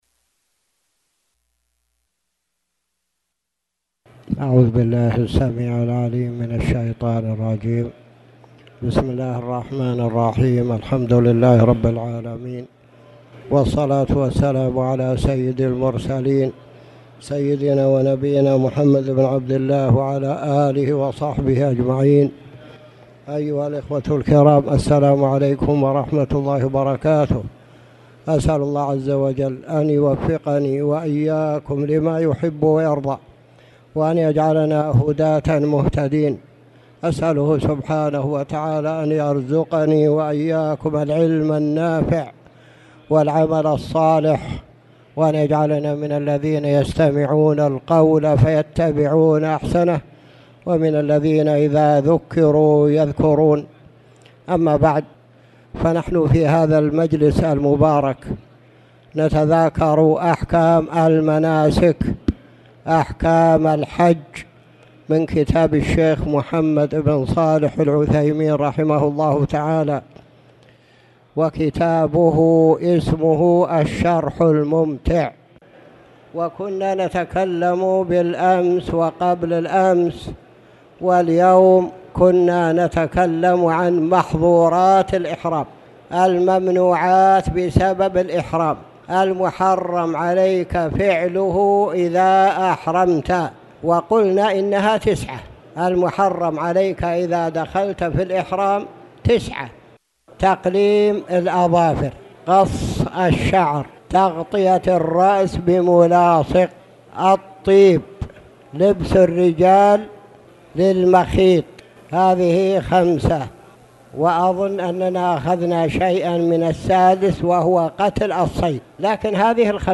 تاريخ النشر ٢٨ ذو القعدة ١٤٣٨ هـ المكان: المسجد الحرام الشيخ